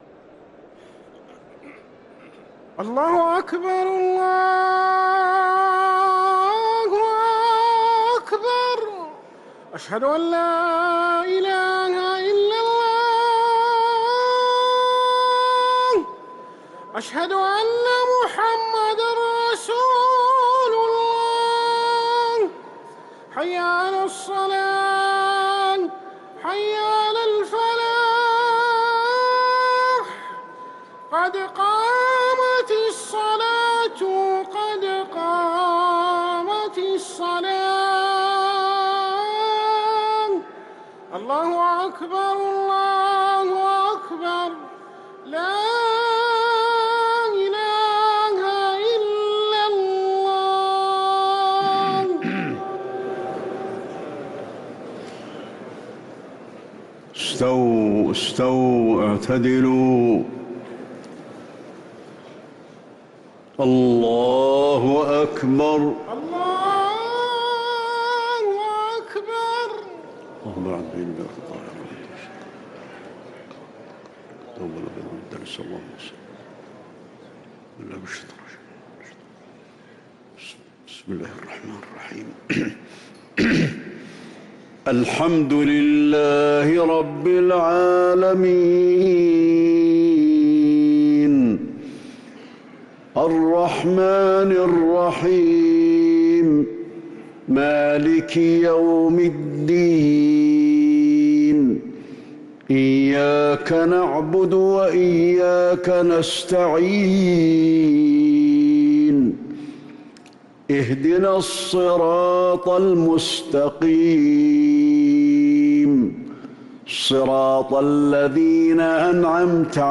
صلاة المغرب للقارئ علي الحذيفي 29 رمضان 1444 هـ
تِلَاوَات الْحَرَمَيْن .